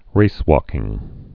(rāskĭng)